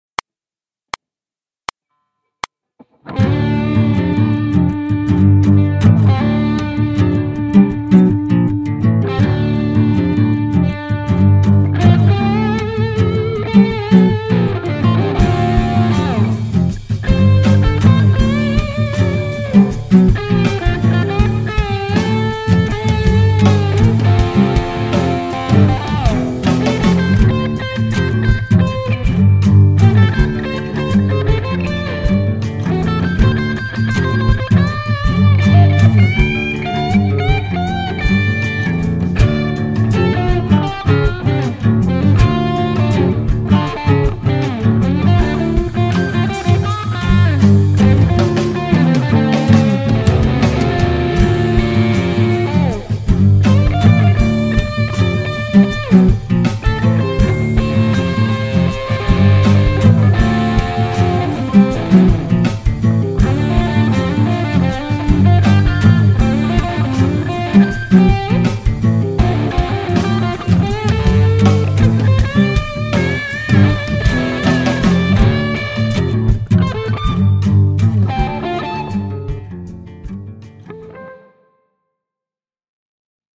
- kun osallistut, soita roots-soolo annetun taustan päälle ja pistä linkki tähän threadiin
Hyvin tällaiseen istuva soundi ja soittotyyli. Toimivaa jammailua.
Mainio soundi. 2p
Rypistystä alusta loppuun ja hyvä soundi ja hyvät fibat.